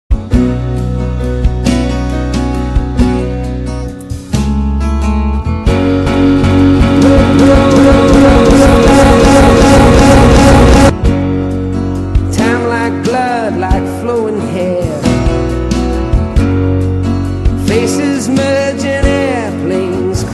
AudioStuttering.mp3